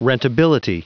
Prononciation du mot rentability en anglais (fichier audio)
Prononciation du mot : rentability